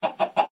sounds / mob / chicken / say3.ogg